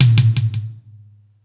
Space Drums(25).wav